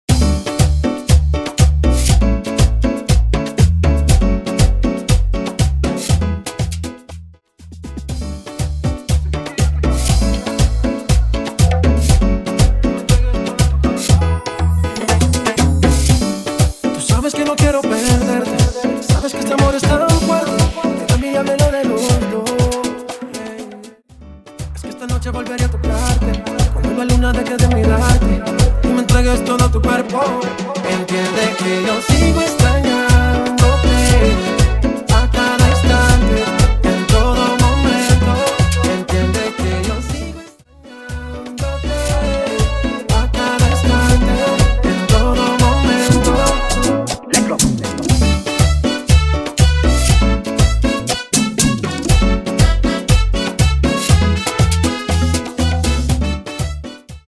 Specializing in Latin genres